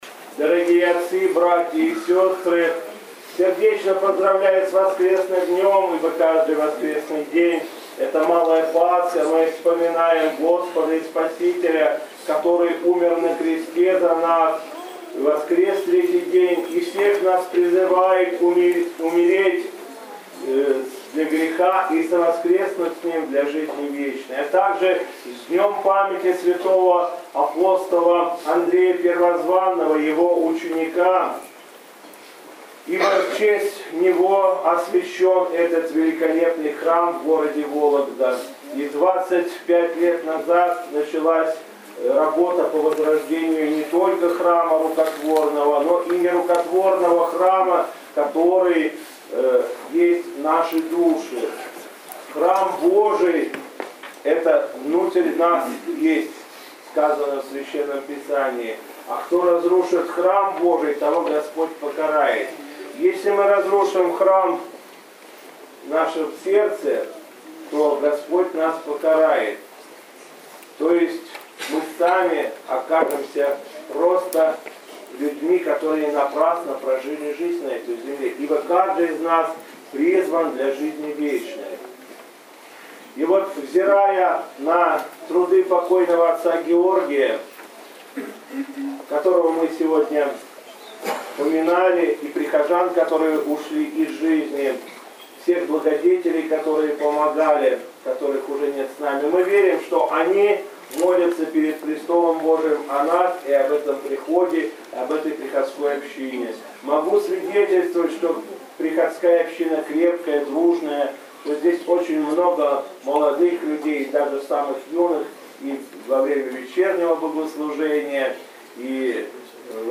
В день памяти апостола Андрея Первозванного митрополит Игнатий совершил праздничную Божественную литургию в храме Андрея Первозванного во Фрязинове.
Владыка Игнатий обратился к прихожанам с архипастырским словом.